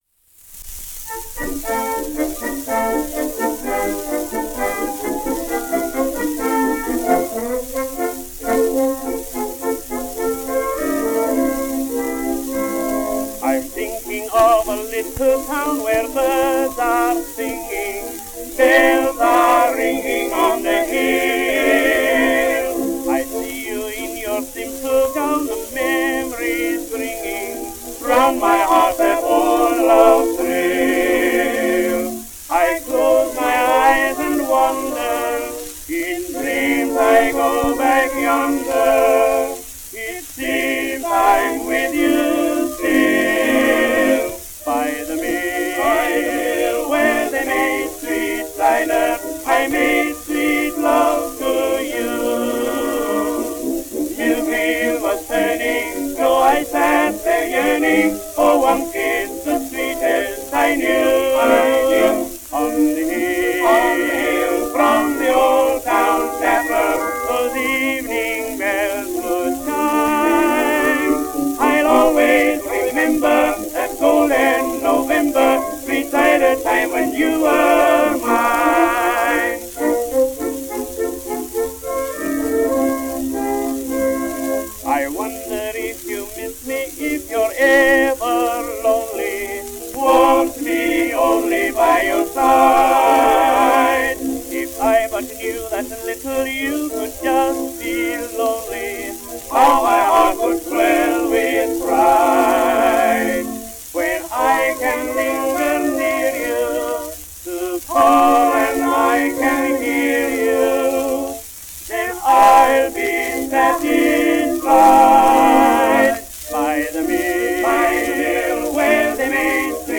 A good deal of recording time was sometimes dedicated to the song lead-in which stated the main theme of a piece.
Sweet Cider Time when You Were Mine by the Peerless Quartet and All Alone as done by John McCormack.  The vocal began with verse then chorus.